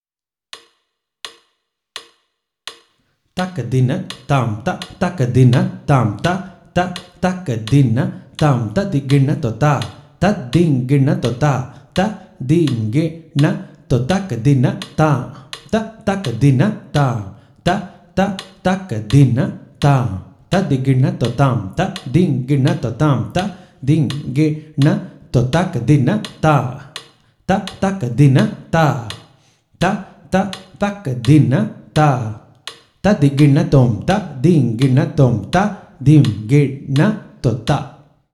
This is a set of 3 theermanams of 15, 16 and 17 Beats, and each theermanam is a combination of chaturashra nadai and trishra nadai.
Konnakol